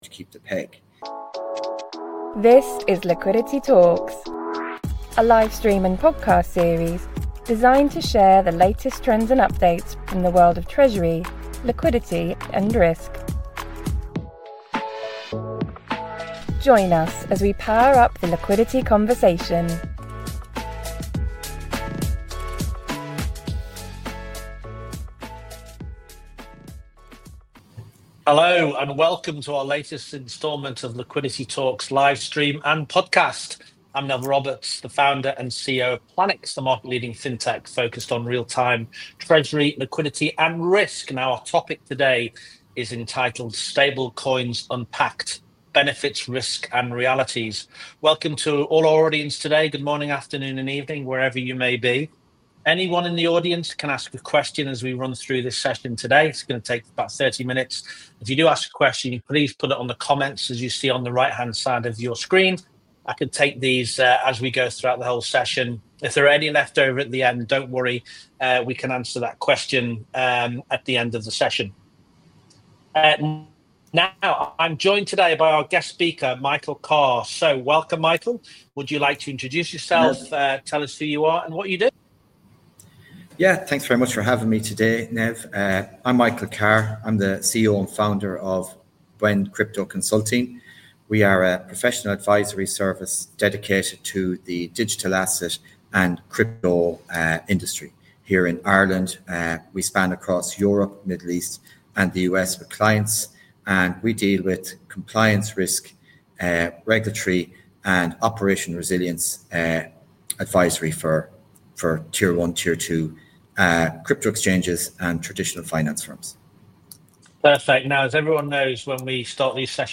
On the latest episode of our Liquidity Talks live series, we’ll be diving in, with a balanced perspective, to explore whether these digital innovations address real operational and financial challenges—or risk introducing new layers of complexity.